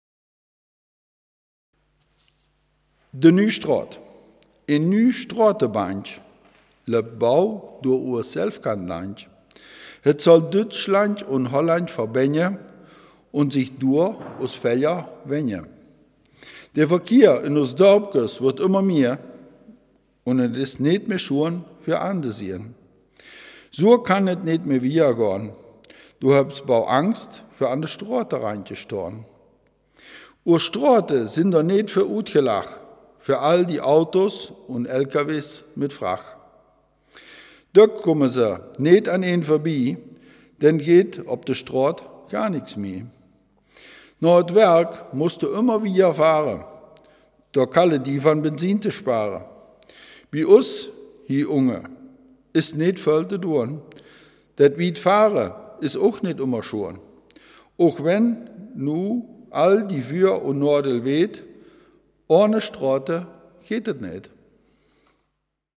Selfkant-Platt